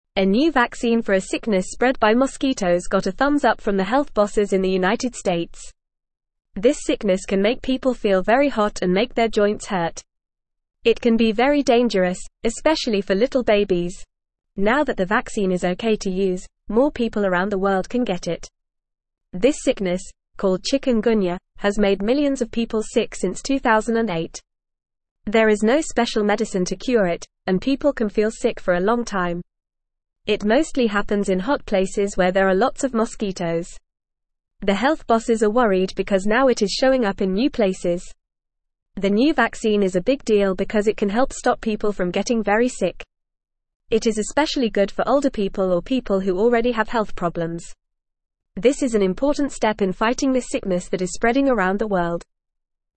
Fast
English-Newsroom-Lower-Intermediate-FAST-Reading-New-shot-to-stop-bad-bug-bite-sickness.mp3